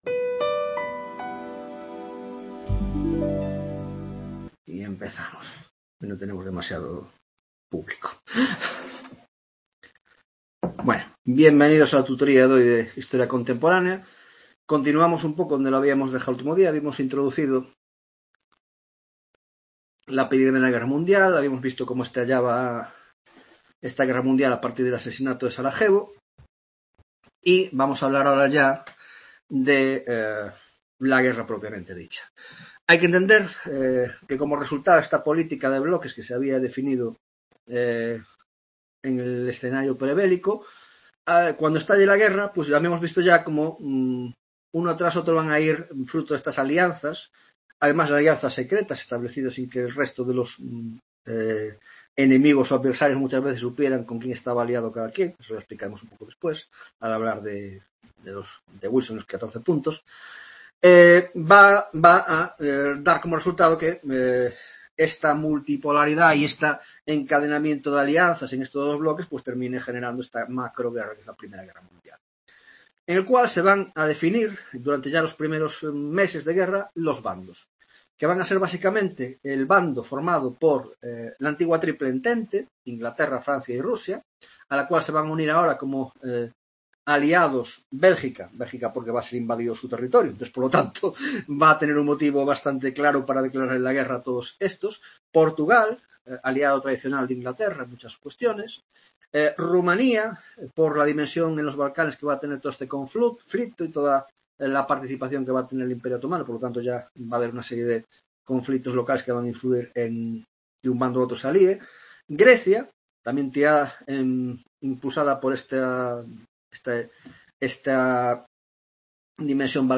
16ª tutoria de Historia Contemporánea - La I Guerra Mundial: Introducción (2ª parte) - 1) Desarrollo y fases de la guerra; 2) La Paz y los Tratados de la Paz (2ª parte)